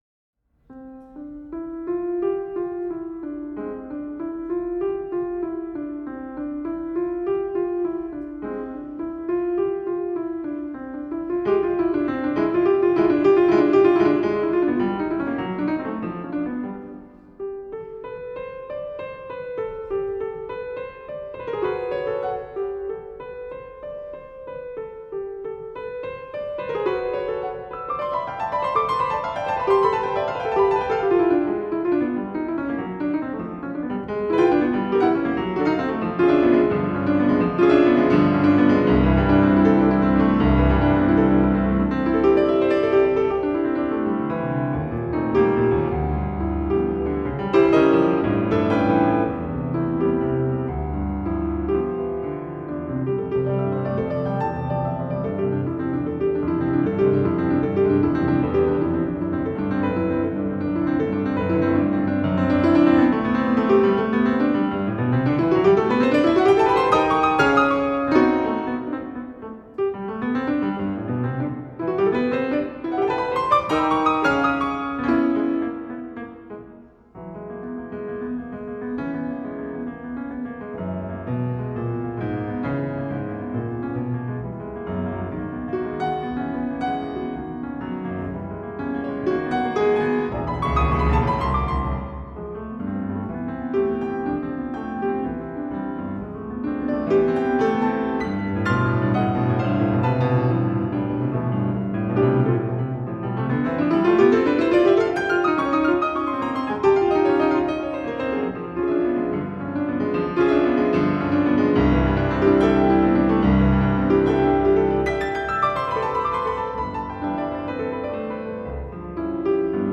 Концертная запись фортепиано (классика)
Записал вот недавно фортепианный концерт, выкладываю тут на ваш суд, хочу услышать критику. Сам слышу что запись не идеальная, позже скажу свои предположения, но хотелось бы еще взгляд со стороны.